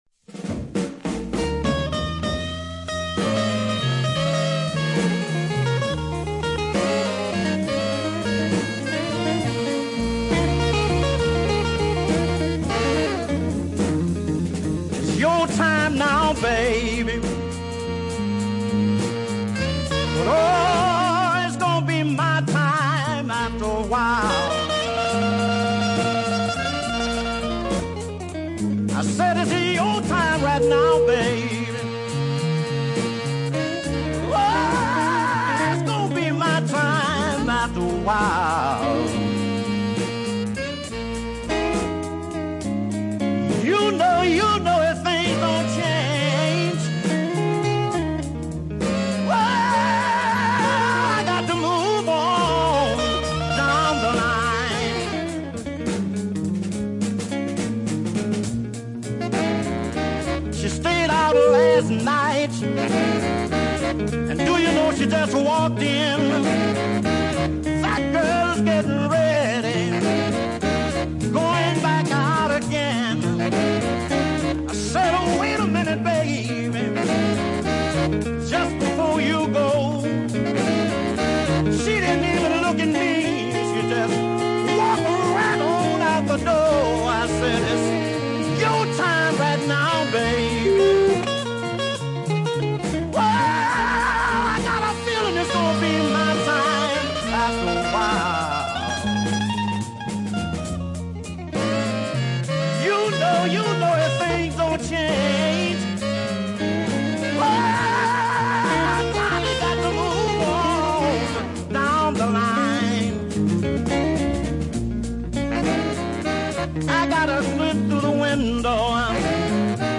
hard tough lead vocals were perfect for the blues
His first release was the excellent slow blues Listen
screaming performance was hair-raiding in its intensity
guitarist